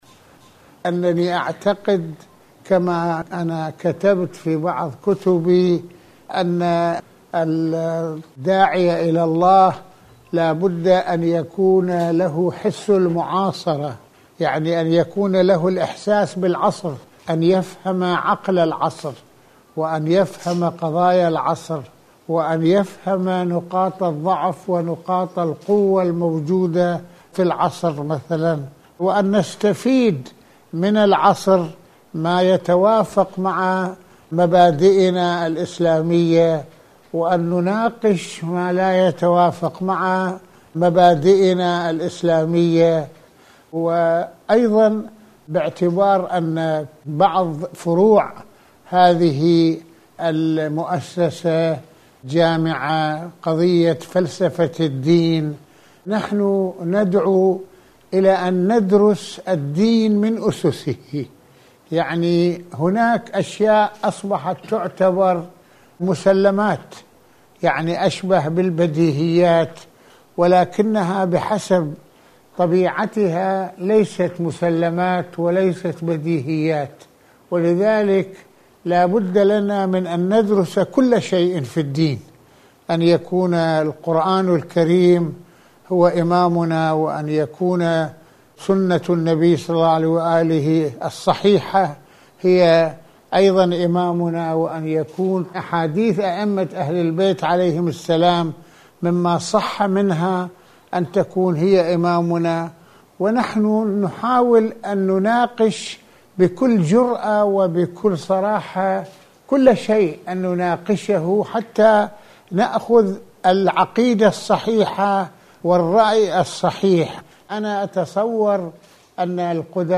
كلمة خلال استقبال ولقاء مع وفد مؤسسة الامام الخميني
المكان : المنزل، بيروت - لبنان